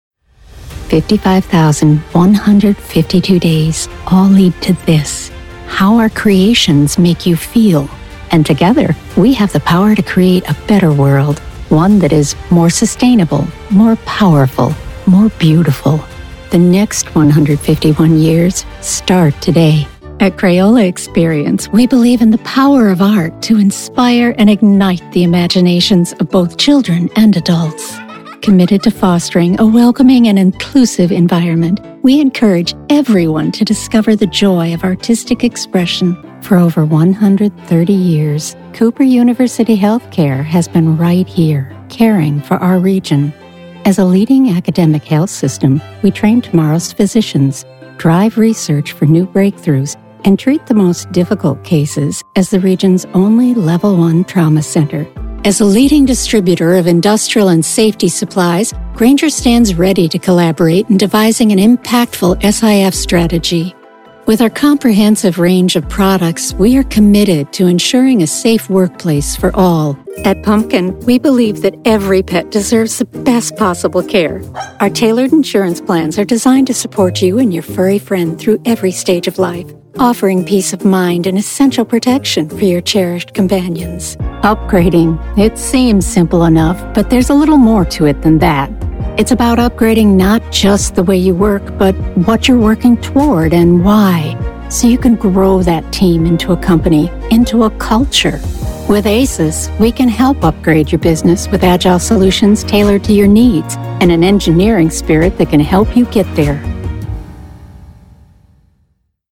Corporate Demo
American Midwest, American-Southern (genl), Irish-American
Middle Aged